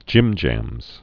(jĭmjămz)